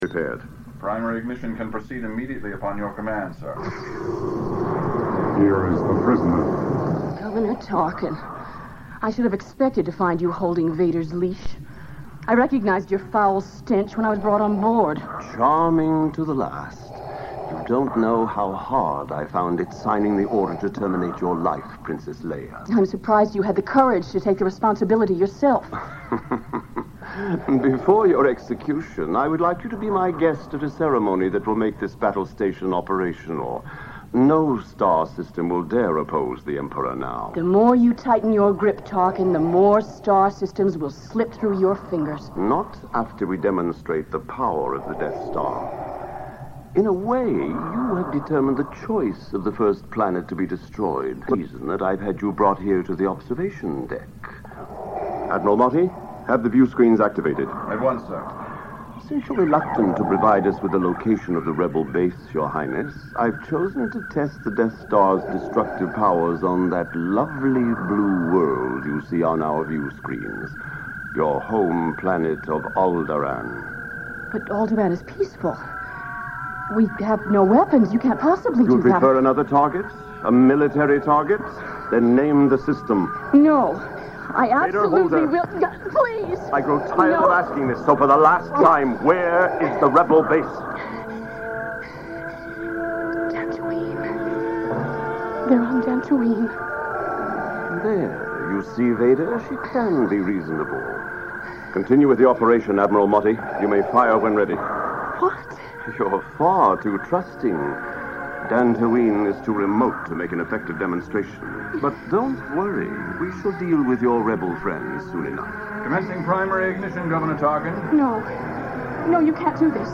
I snagged all ten weekly episodes and edited out the intros, recaps, and teasers to make it continuous, on two C-90's, and two C-60'S. Early 80's aircheck. 5hr total!